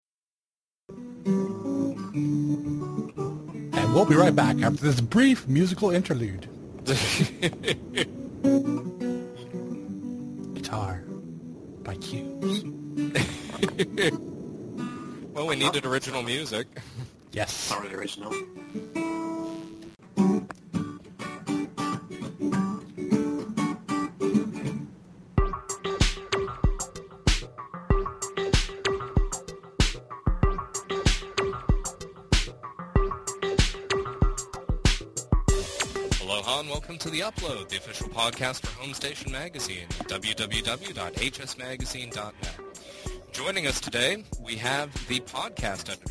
HomeStation Presents: The Upload, Episode #20 — An Interview With Ralph Baer | HomeStation Magazine
In this episode of The Upload , we interview the man who invented the video game: Ralph Baer .